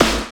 20 SNARE 2-L.wav